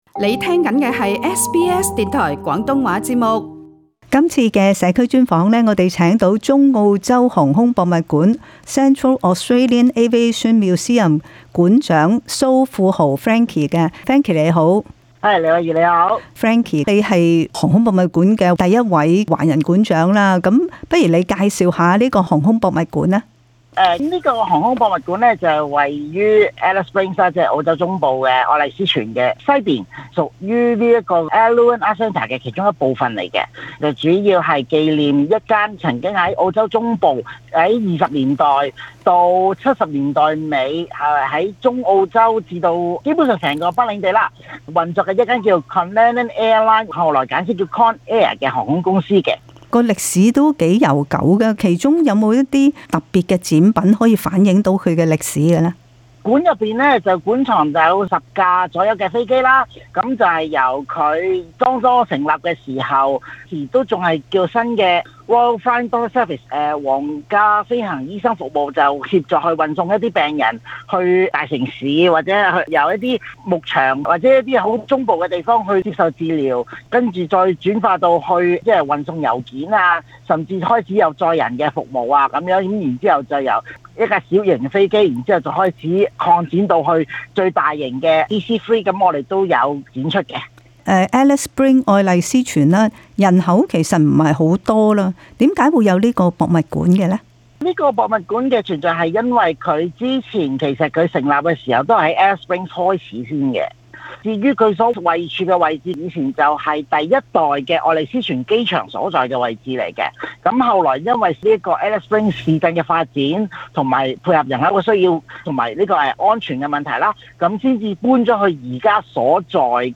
請大家一齊聽聽這節「社區訪問」。